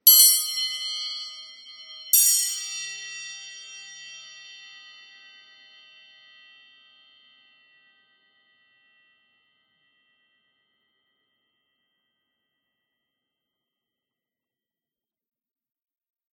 Schwingstäbe-Satz (5 Stk.), Eisen, im Futteral
Handgetriebene Schwingstäbe aus Eisen in 5 verschiedenen Größen.
Sie klingen bei gegenseitiger Berührung,  wobei das Schwingen dem Ton besondere Lebendigkeit gibt.
Man kann die Stäbe aber auch, ähnlich wie Triangeln, einzeln mit einem geeigneten Schlägel  (z.B. Nr.376200) anschlagen.
Schwingstab.mp3